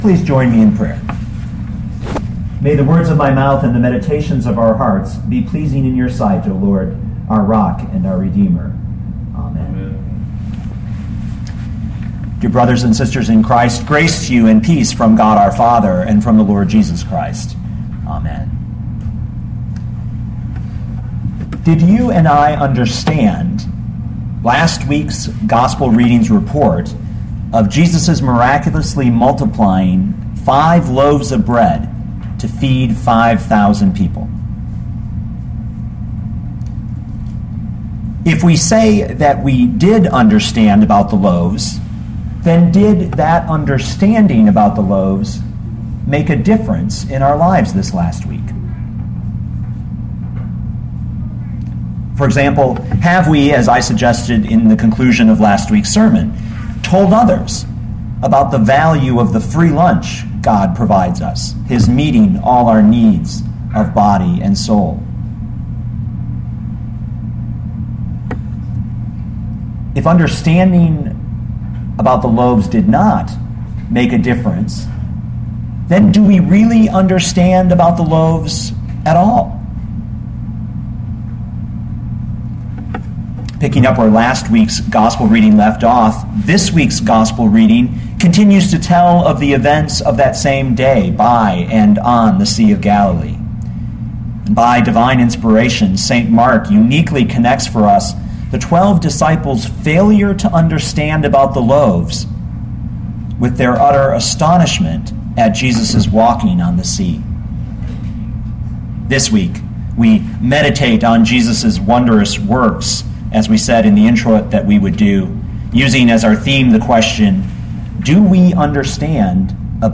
2012 Mark 6:45-56 Listen to the sermon with the player below, or, download the audio.